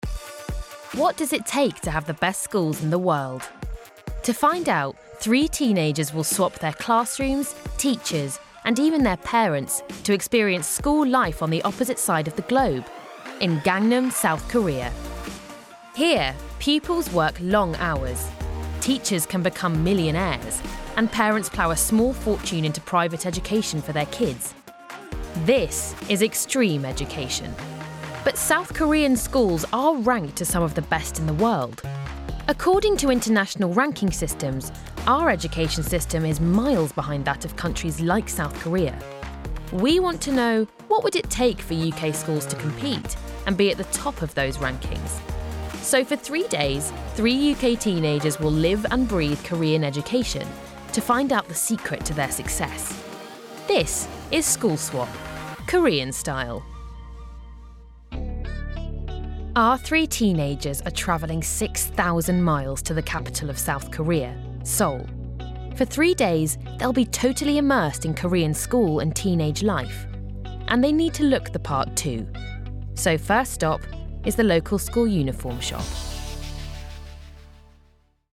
Narration Reel
• Native Accent: RP
• Home Studio